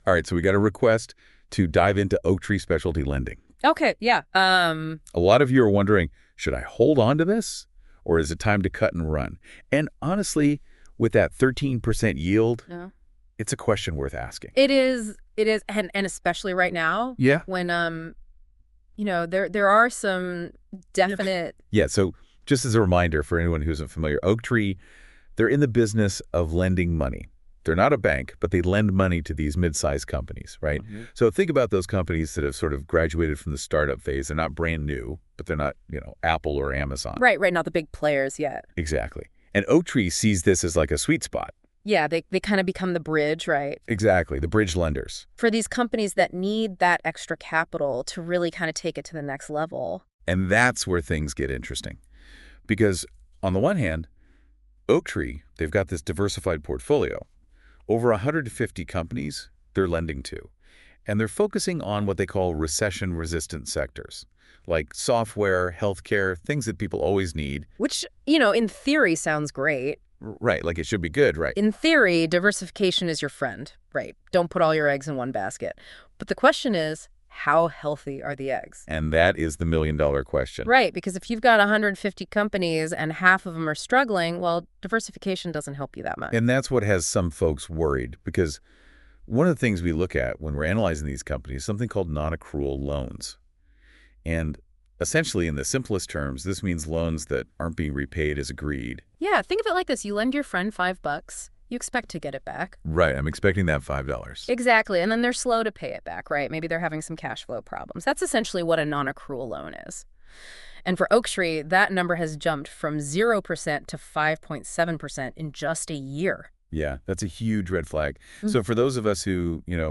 You can make a 2 person AI generated podcast with this tool and it sounds pretty good.